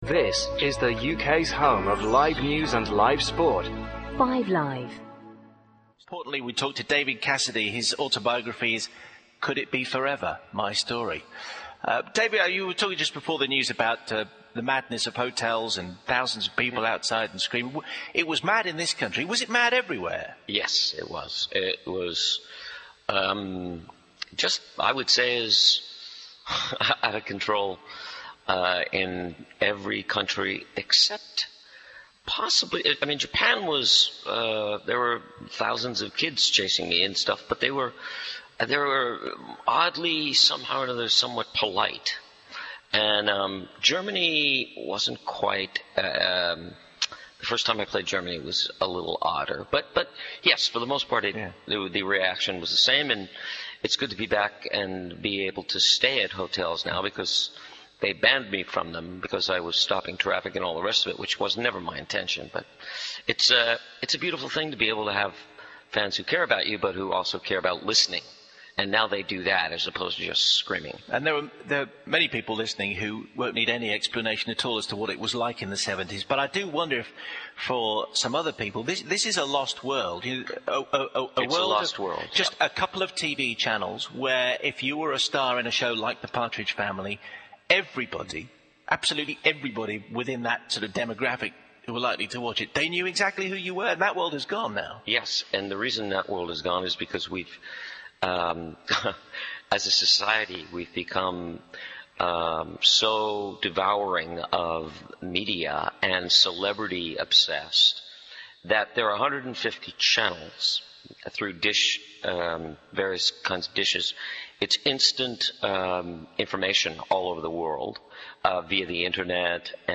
Classic Gold Radio. Recorded in Las Vegas when David was starring in At The Copa.